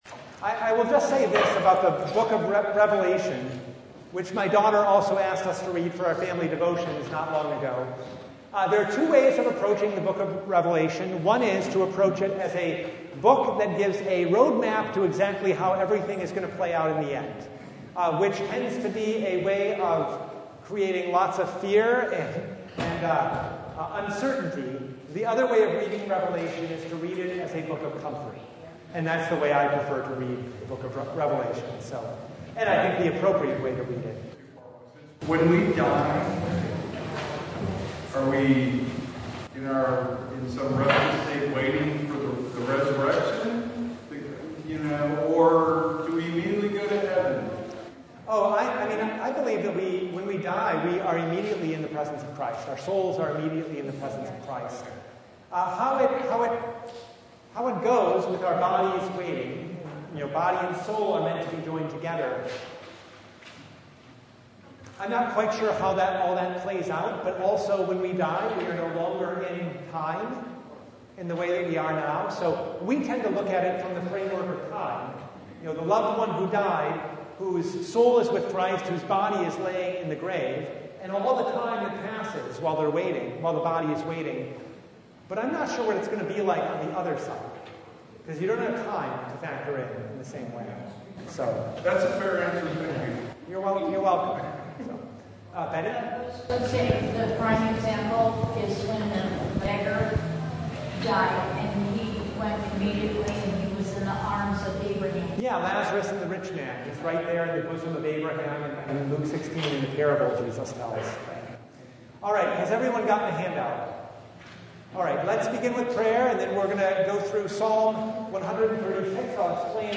Psalm 136 Service Type: Bible Study Give thanks to the Lord